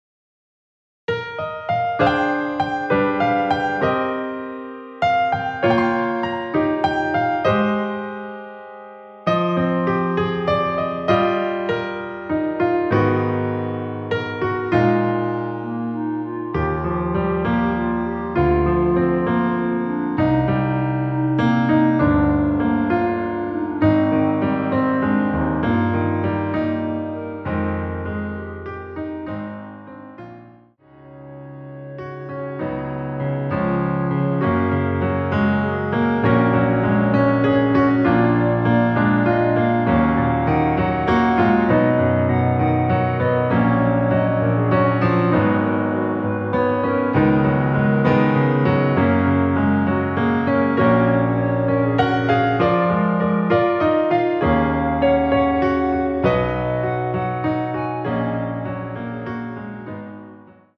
원키에서(-2)내린 멜로디 포함된 MR입니다.
Eb
앞부분30초, 뒷부분30초씩 편집해서 올려 드리고 있습니다.
중간에 음이 끈어지고 다시 나오는 이유는